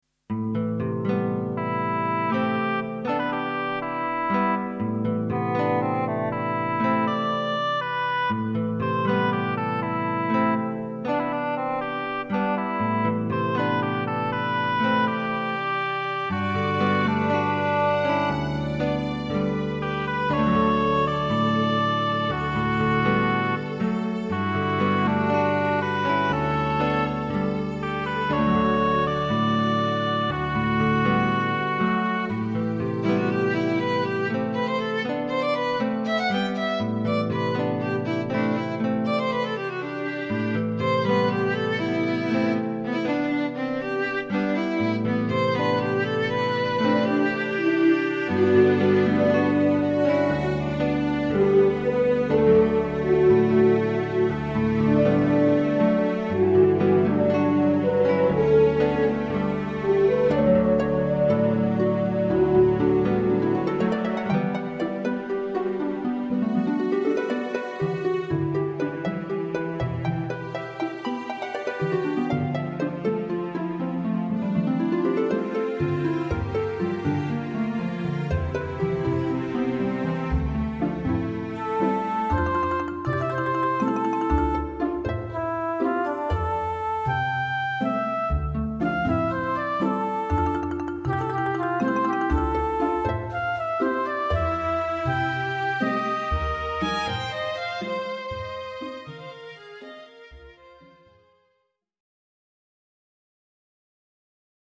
klassiek
Wandeling door een nevelig bos, rustiek, achtergrond